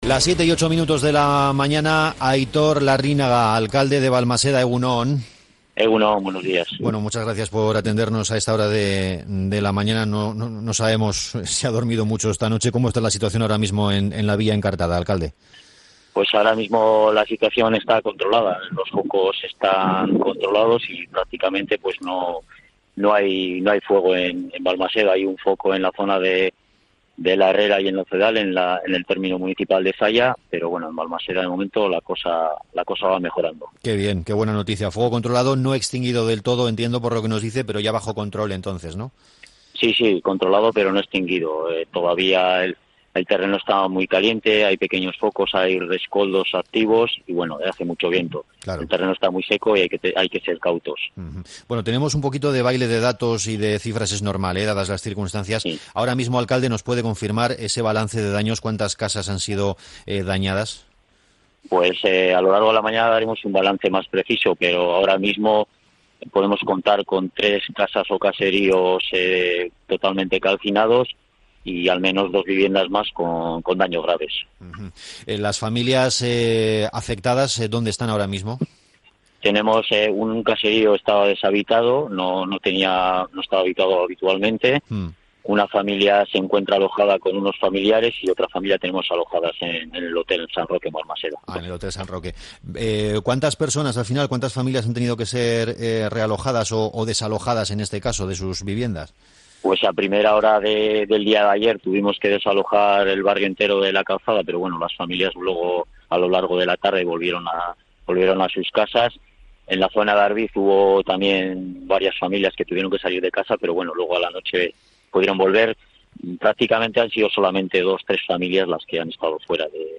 Aitor Larrinaga, alcalde de Balmaseda: "La situación está controlada. No hay fuego en Balmaseda"